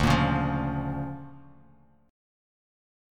DmM7#5 Chord
Listen to DmM7#5 strummed